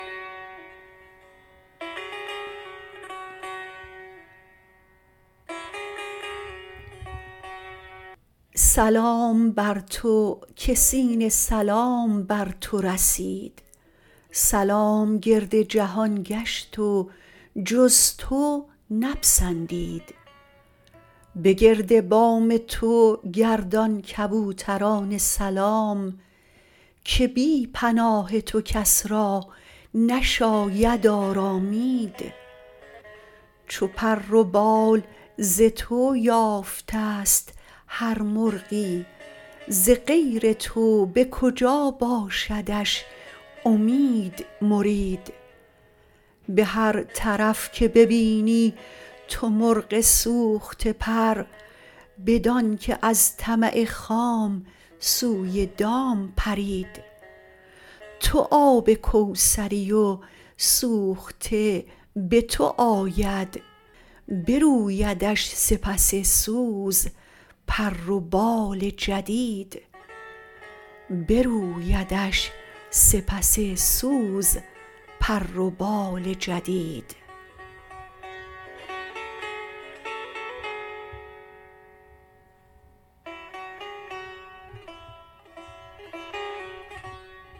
مولانا » دیوان شمس » غزلیات » غزل شمارهٔ ۹۵۵ با خوانش